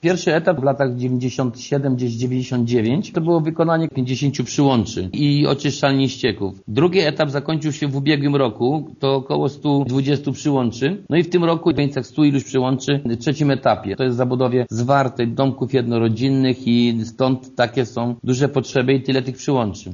Będzie to już trzeci etap tej inwestycji – przypomina wójt Gminy Krzywda Jerzy Kędra: